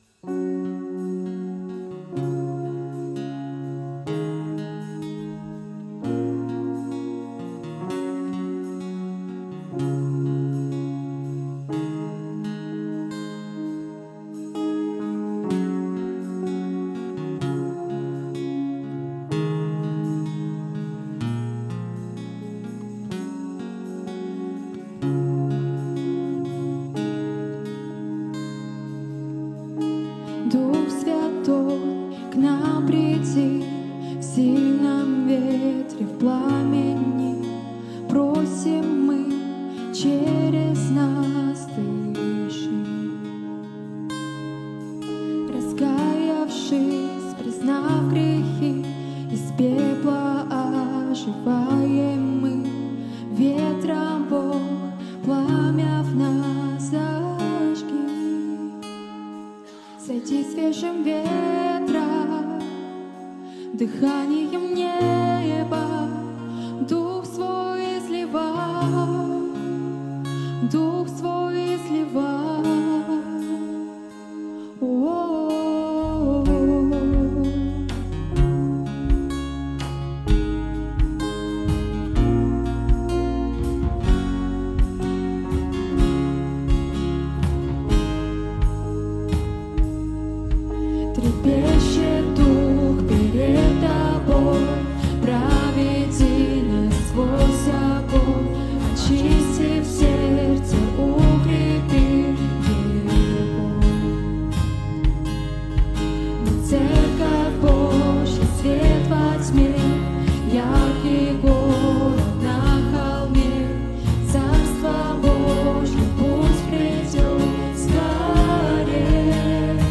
264 просмотра 240 прослушиваний 10 скачиваний BPM: 135